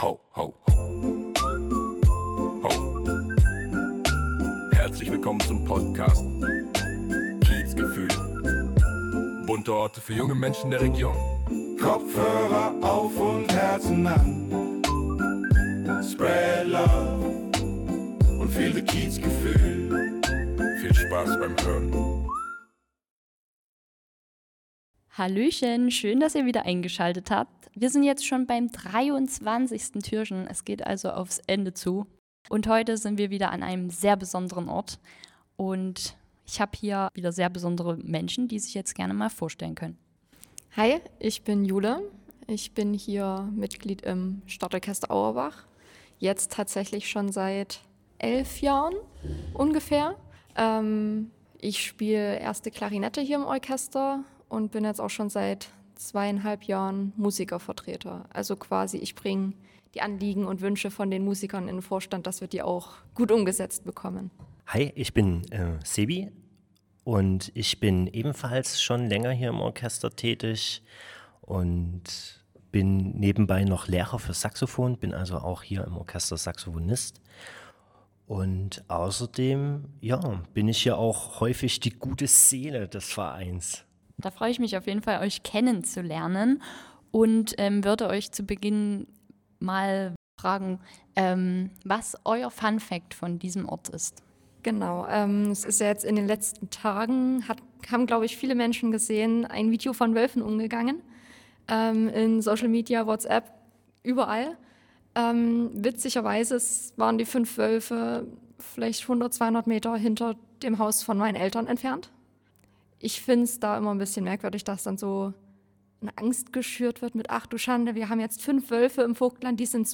Ein kleines exklusives Konzert für unsere Hörer:innen.